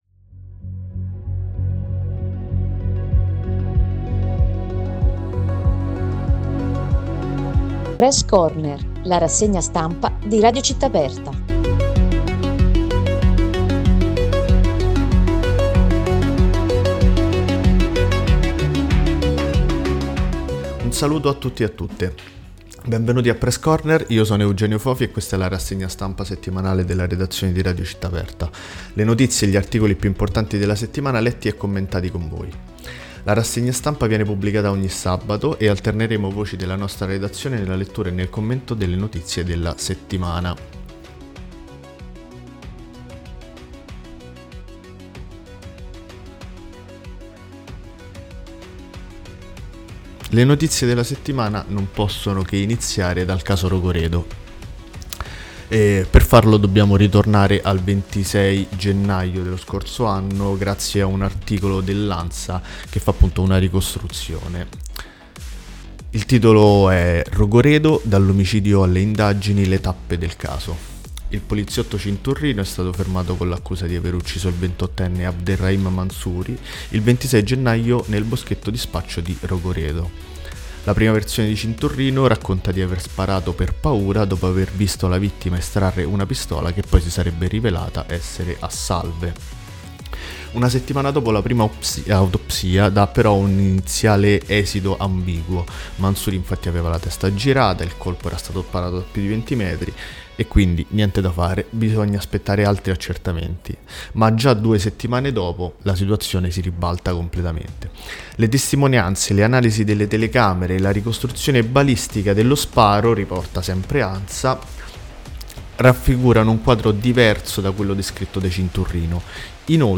Torna Press Corner, rassegna stampa commentata a cura della redazione di Radio Città Aperta. Ogni sabato, in diretta alle 9 e poi in podcast, una selezione di notizie che raccontano i fatti eclatanti della settimana.